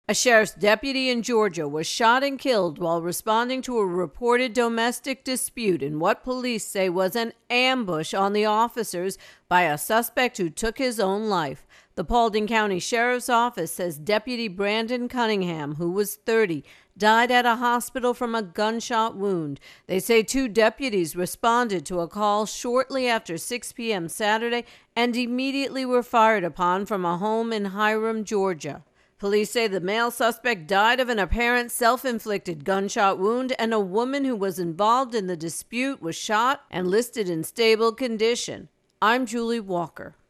reports on a 30-year-old Georgia deputy killed in shooting over the weekend.